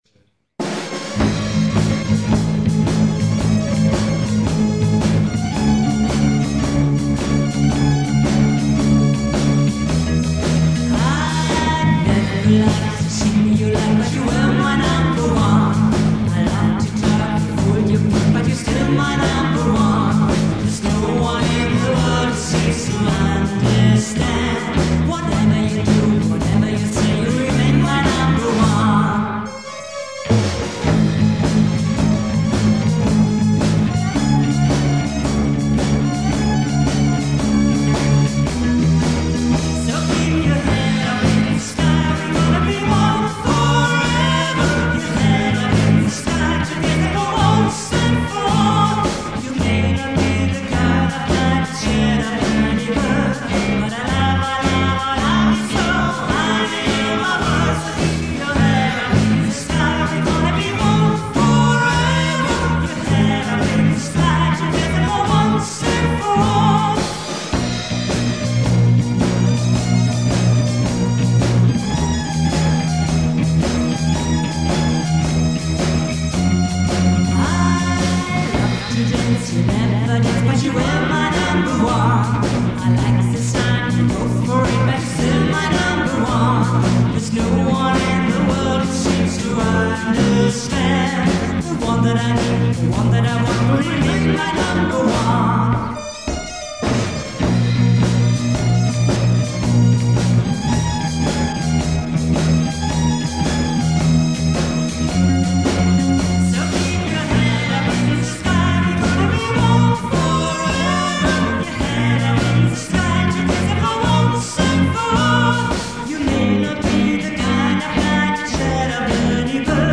Livemitschnitt während eines Tanzabends in Wiesede/Ostfriesland.
Gitarre und Gesang
Keyboard und Gesang
Schlagzeug und Gesang
Bass und Gesang
Die Aufnahmen haben im Laufe der Jahrzehnte doch schon etwas gelitten.